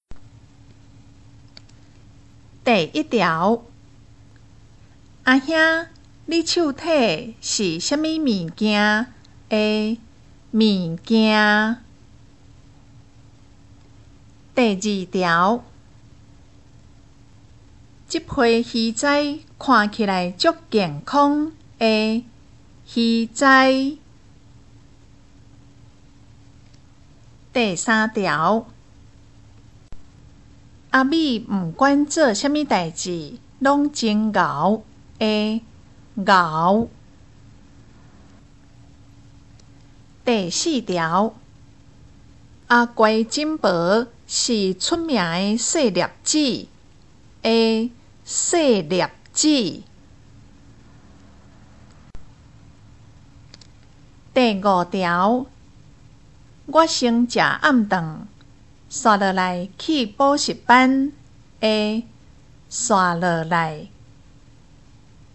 【國中閩南語3】每課評量(2)聽力測驗mp3